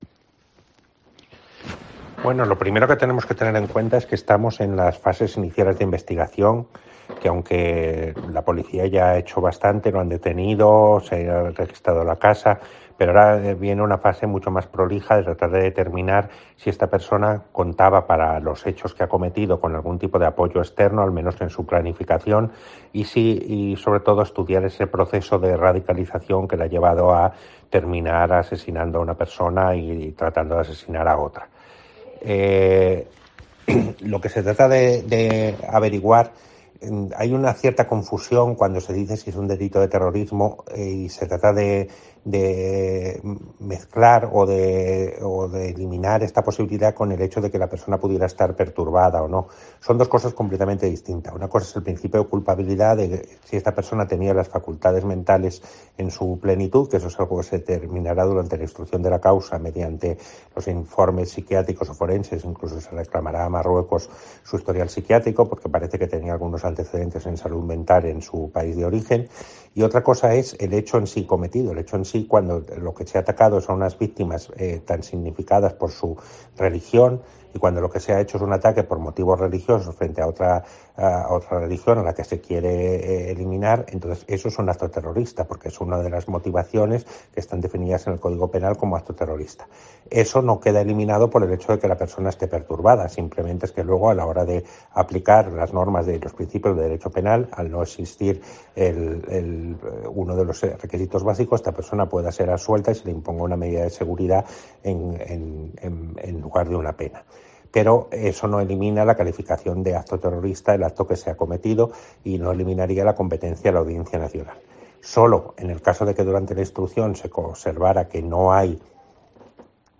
Fiscal Jefe de Algeciras - Juan Cisneros, sobre el asesinato en Algeciras